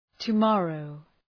Προφορά
{tu:’mɔ:rəʋ}